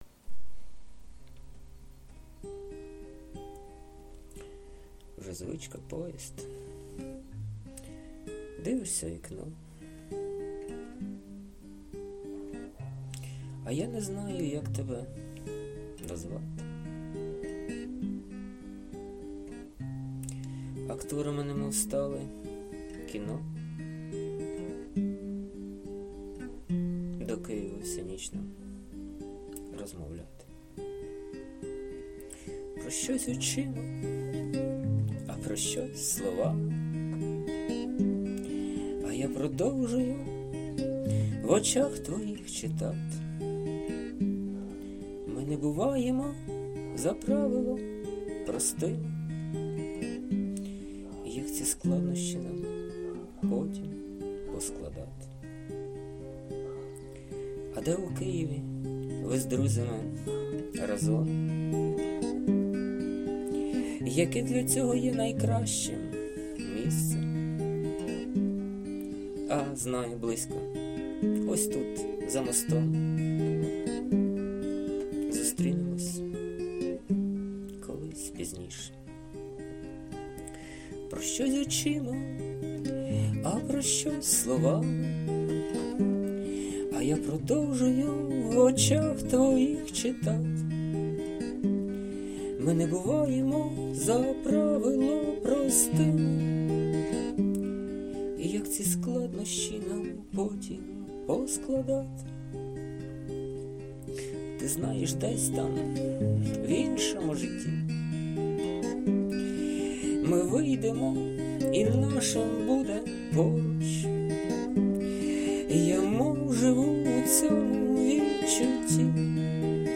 Власне виконання.
запис зроблено на звичайний телефон.
hi 16 дякую ... то я так хворію ... з гітарою ... 16
12 Сподобалась пісня, гарне виконання! 42 16 43